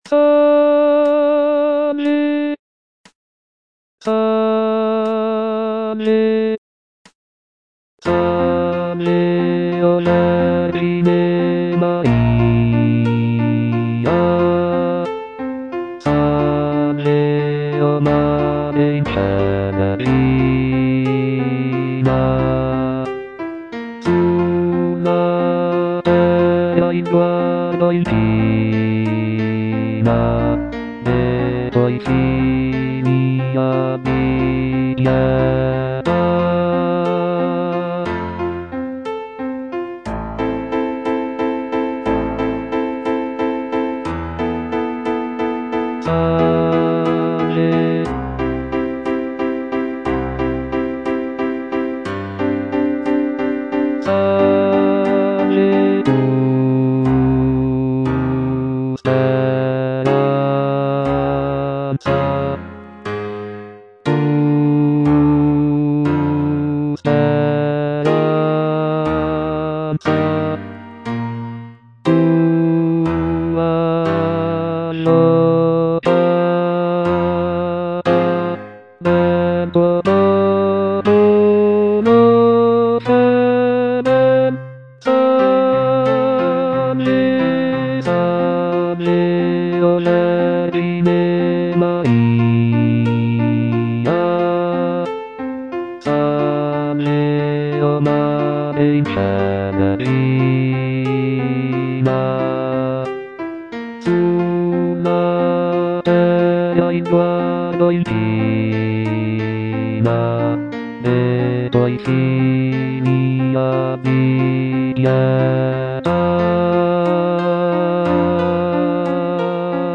G. ROSSINI - SALVE O VERGINE MARIA (EDITION 2) Bass (Voice with metronome) Ads stop: auto-stop